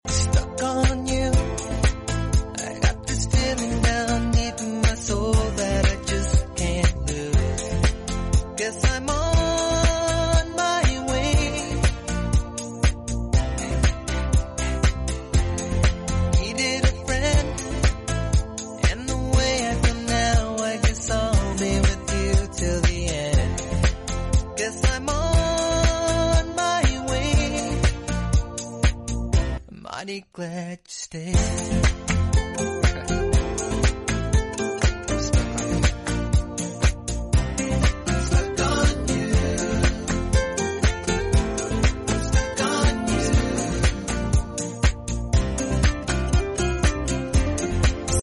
Naif road, deira,Dubai sound effects free download